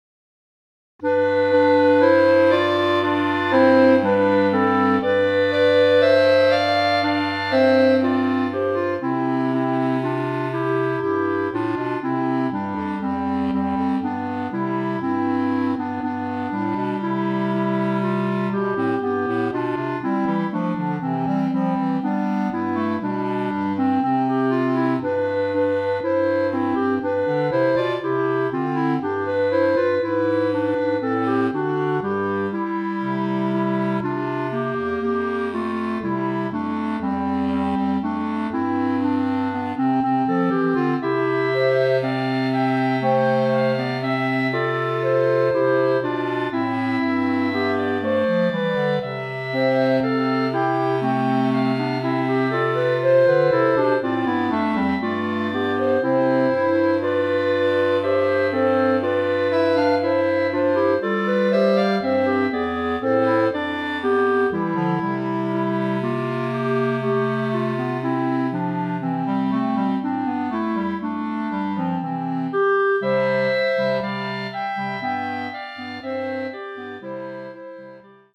Voicing: Clarinet Quartet